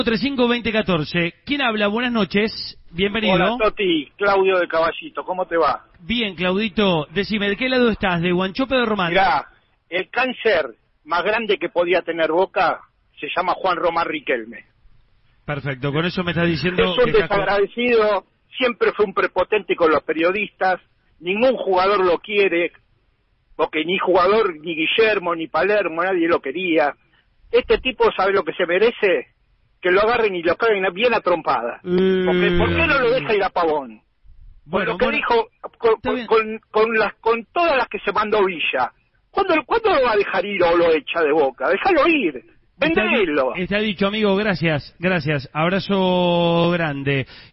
En La Red hay un oyente anti riquelme que dijo “El peor cancer de boca” “Hay que cagarlo bien a trompadas”.
Estaba re caliente el viejo kjjj
Por la voz tiene pinta de ser +50 - supongo que los de ese rango de edad tienden a ser un poco más sensatos.